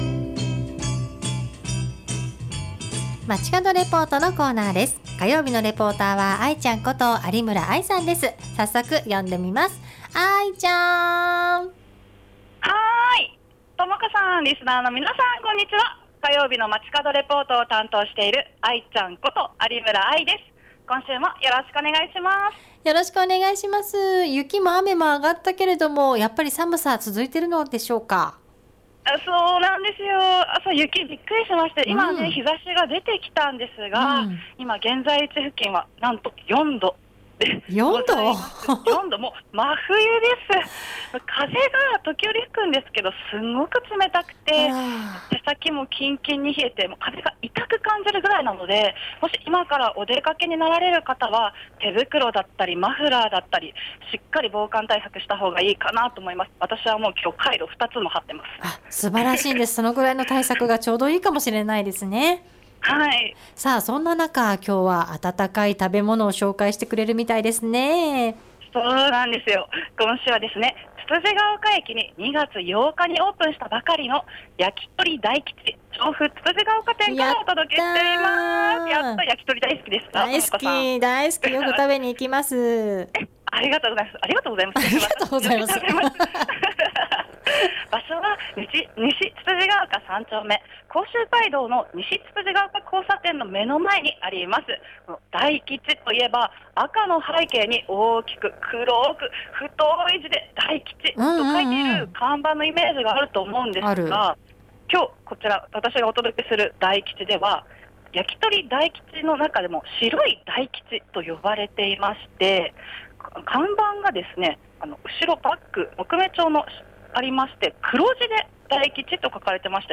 今週は２月８日にオープンした焼き鳥居酒屋「やきとり大吉調布つつじヶ丘店」からお届けしました！